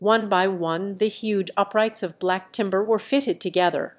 Matcha-TTS - [ICASSP 2024] 🍵 Matcha-TTS: A fast TTS architecture with conditional flow matching
VITS_4.wav